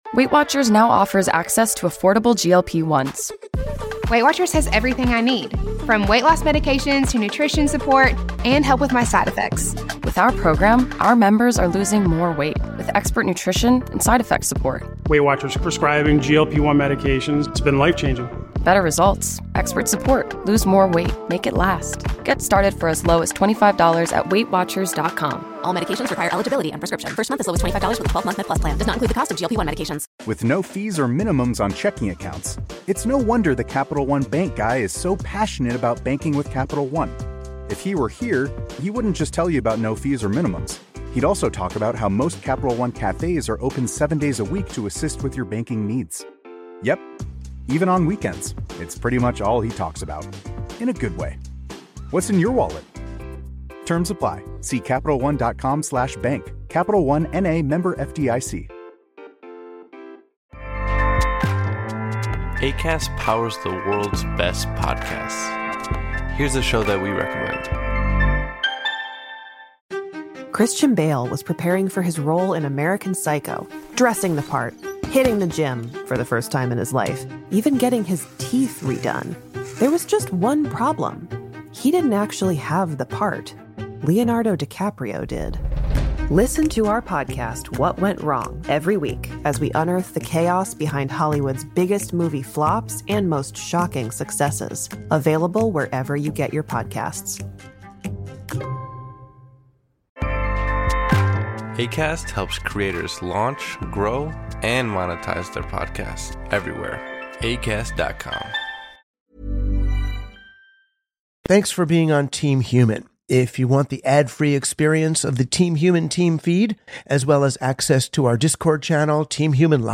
🎙 In his monologue, Rushkoff advocates for a holistic understanding of life - not just individualized, mechanized processes.